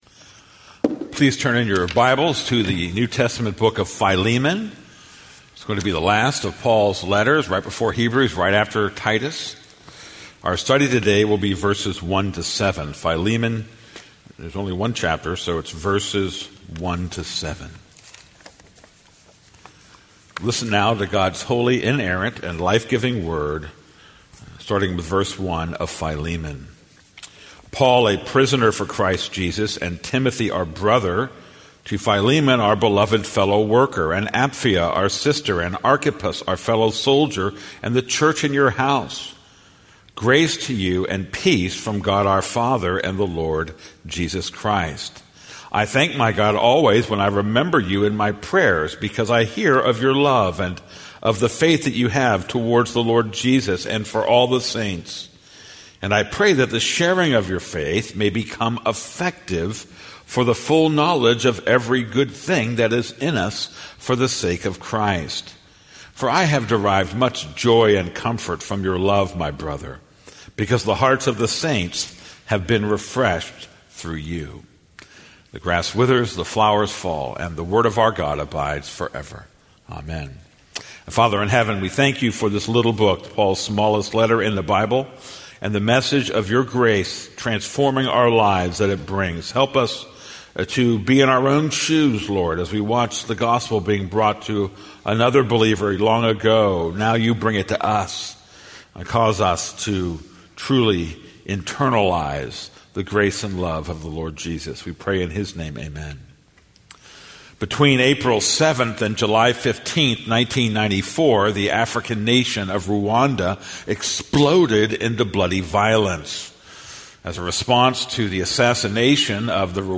This is a sermon on Philemon 1:1-7.